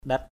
/ɗɛt/ (d.) ngọc, đá thiêng = gem, precious stone, sacred stone sa baoh ndait saktajai (IPT) s% _b<H =QT xKt=j một viên ngọc linh thiêng. a sacred precious stone. ndait...